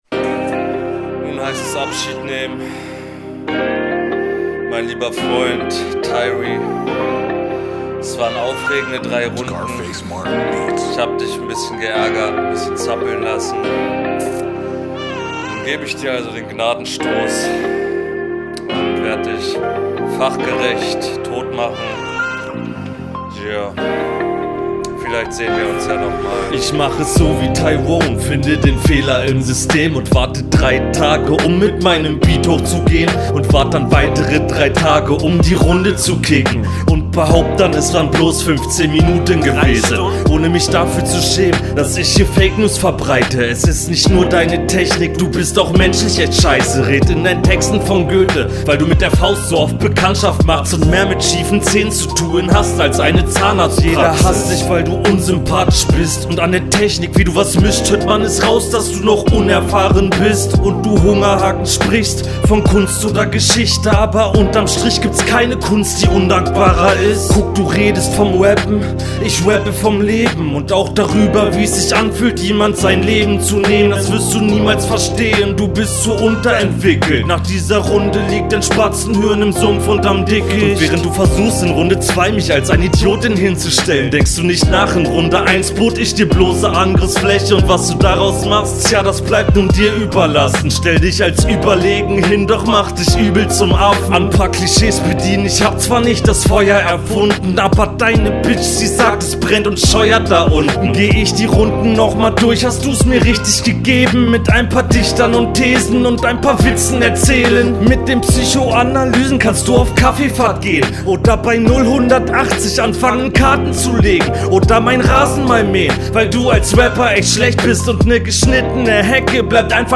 Stimme wieder ruhig, leider. Textlich und Abmische schön sauber.